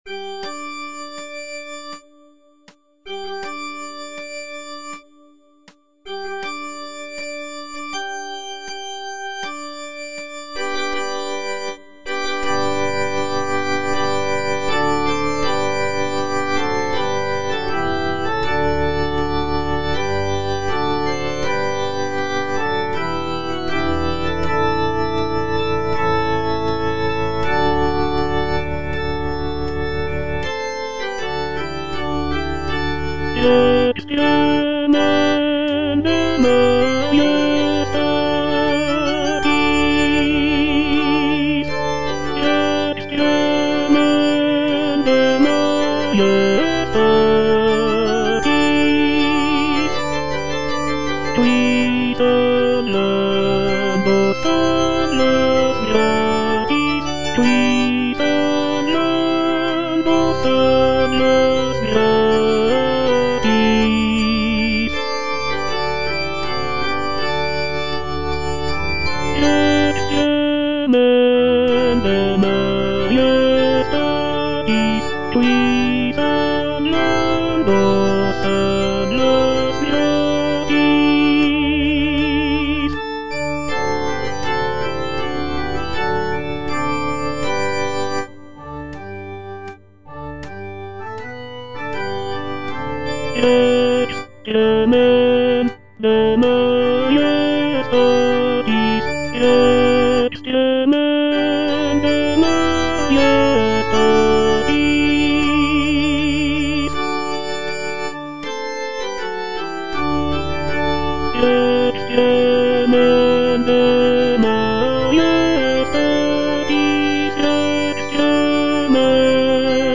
(tenor II) (Voice with metronome) Ads stop
is a sacred choral work rooted in his Christian faith.